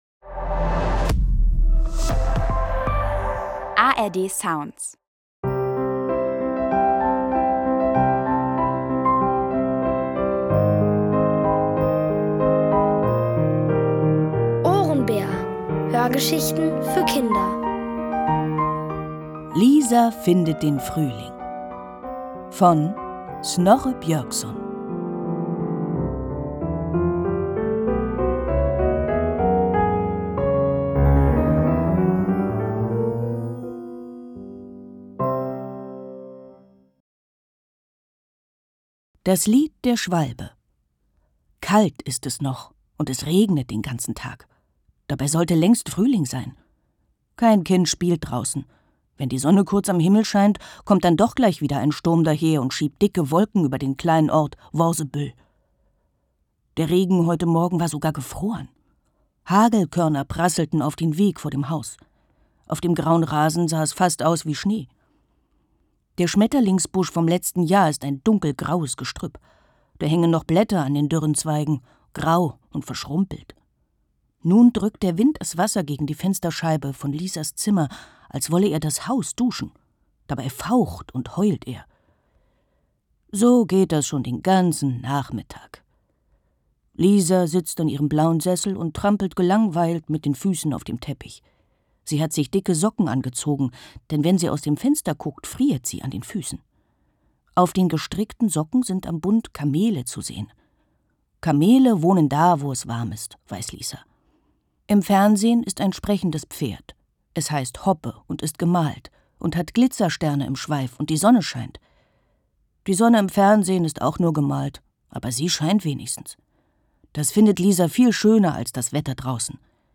Lisa findet den Frühling | Die komplette Hörgeschichte!